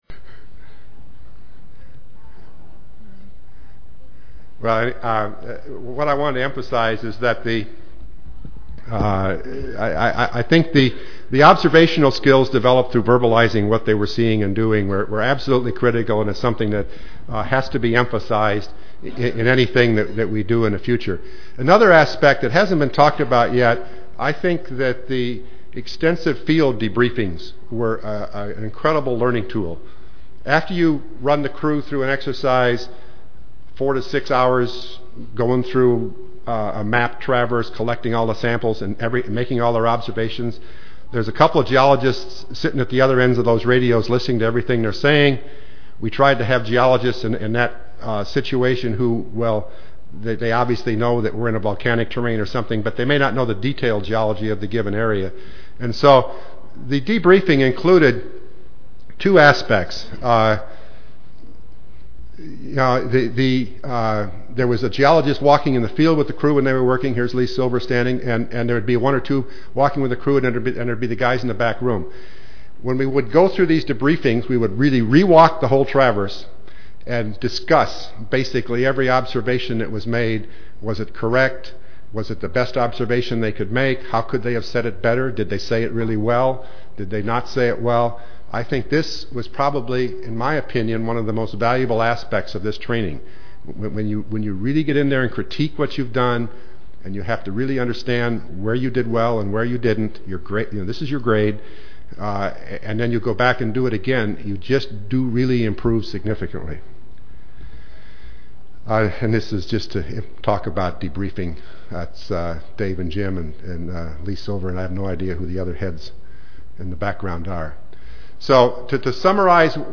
See more from this Division: Topical Sessions See more from this Session: The Role of Field Geology and Geophysics in the Return to the Moon